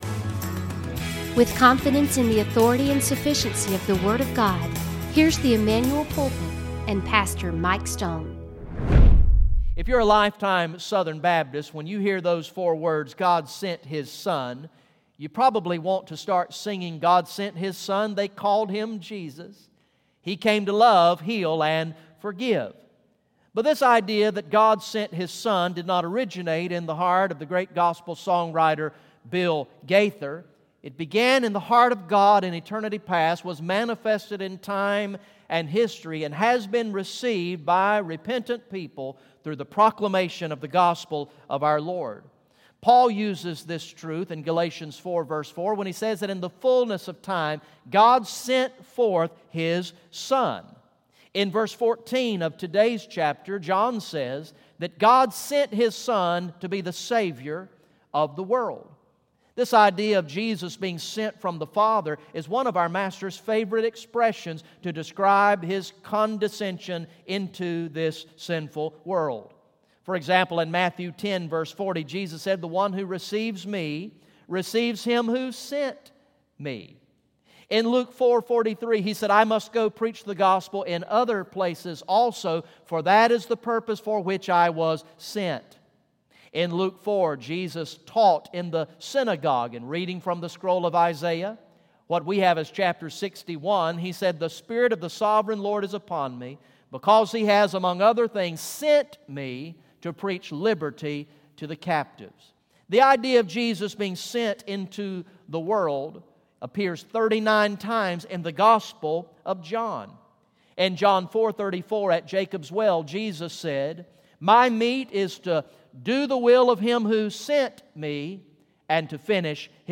The teaching ministry